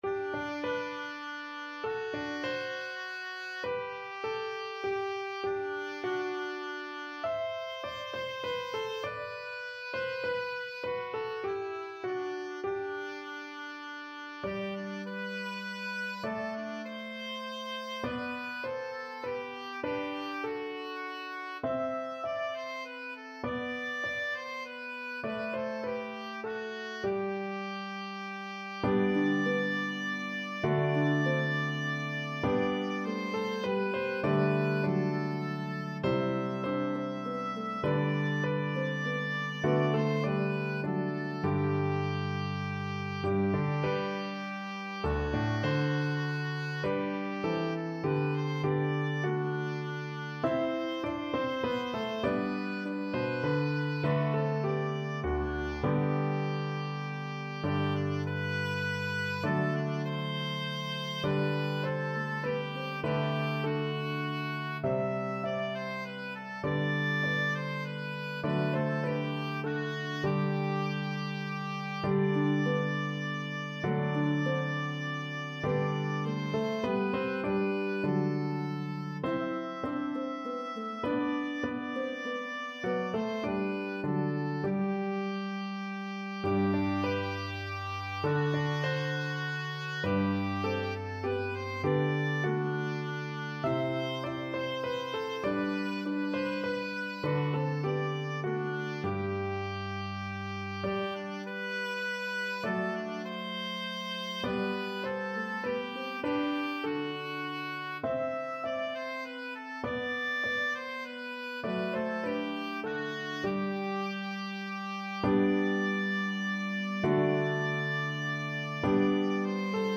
traditional round
Harp, Piano, and Oboe version